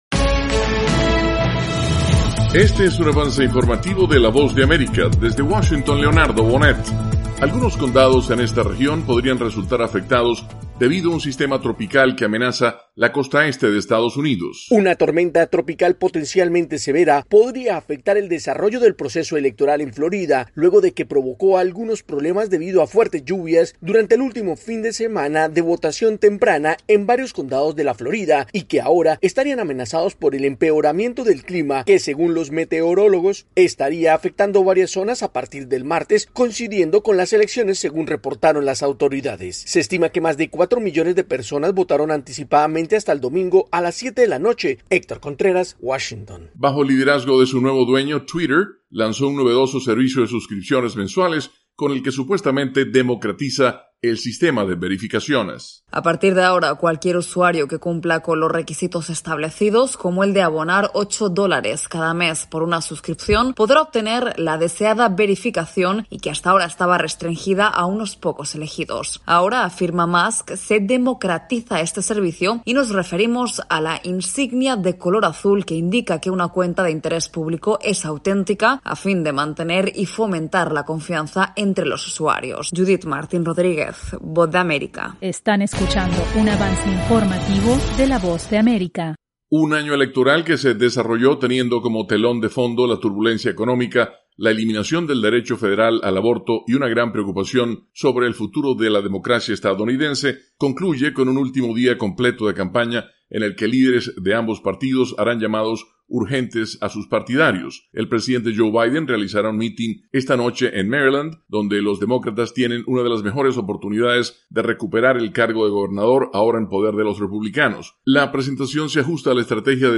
El siguiente es un avance informativo presentado por la Voz de América, desde Washington,